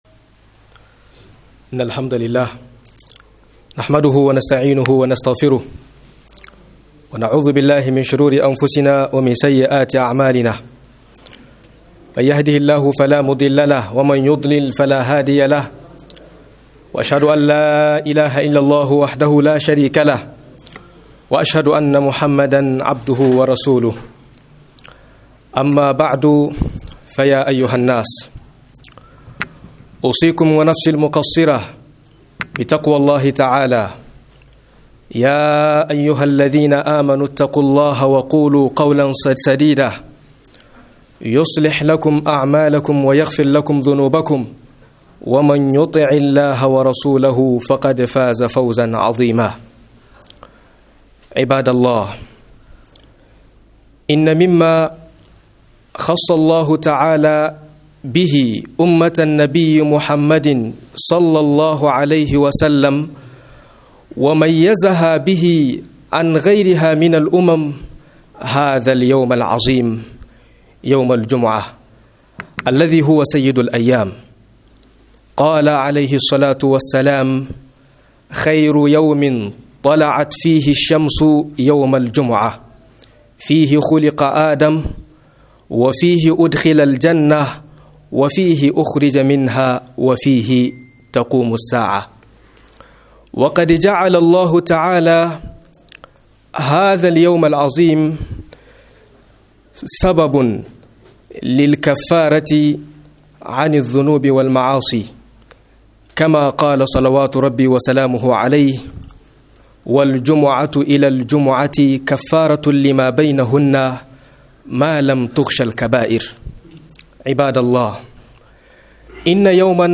03 KURAKURAN MUTANE A JUMA'A - MUHADARA